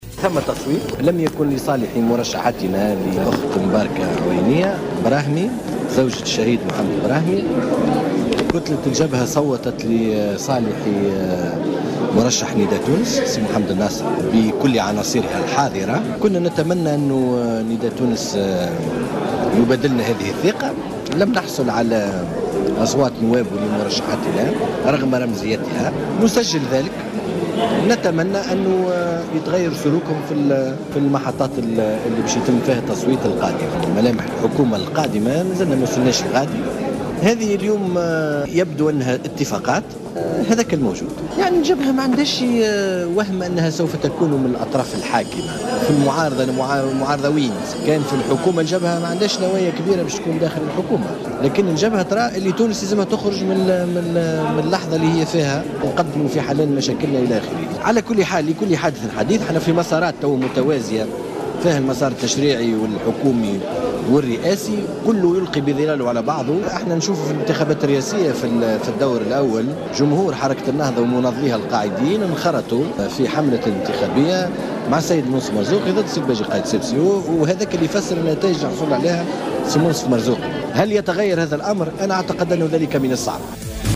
Le dirigeant du Front Populaire (FP) et secrétaire général du Parti unifié des patriotes démocrates, Zied Lakhdhar s’est exprimé jeudi au micro de Jawhara Fm à l’issue de l’élection de Abdelfatah Mourou au poste de vice-présidente de l’Assemblée des représentants du peuple (ARP) par 157 voix contre 33 voix pour la candidate du FP, Mbarka Aouania Brahmi.